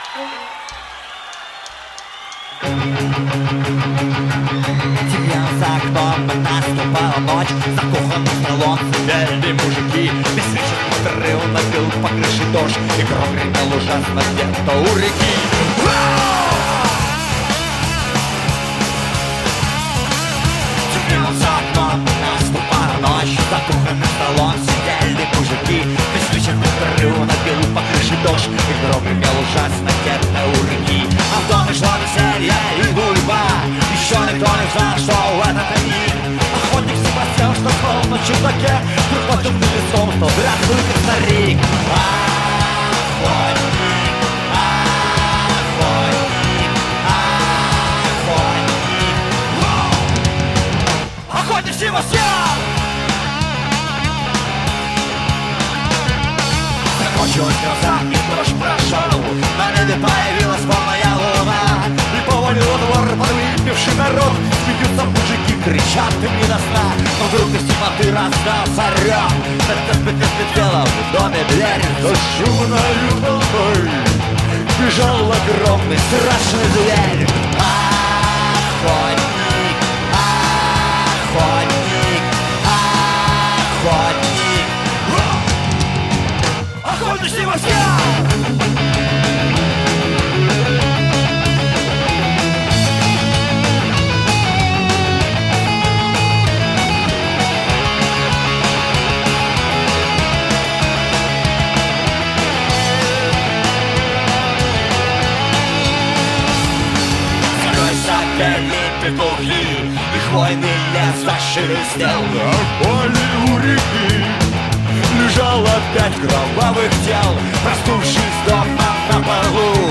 Жанр: Метал / Рок